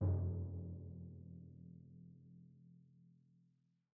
Timpani Large
Timpani7D_hit_v2_rr1_main.mp3